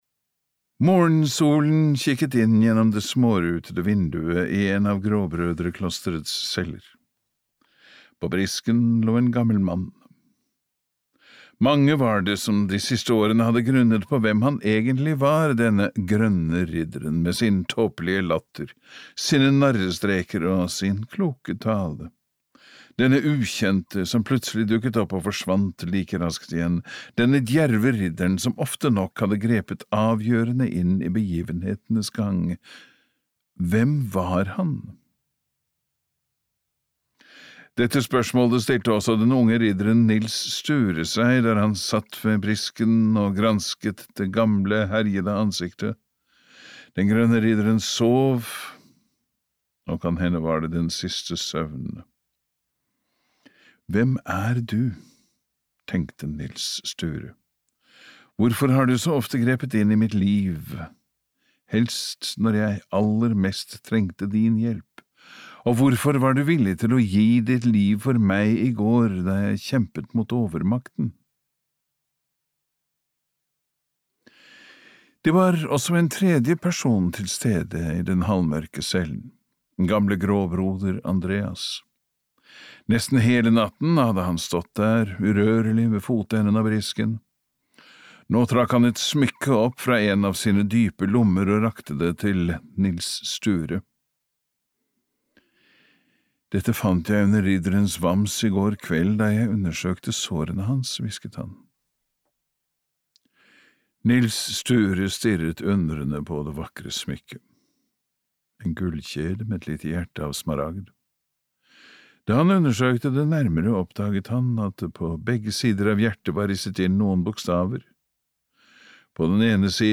Testamentet (lydbok) av Carl Georg Starbäck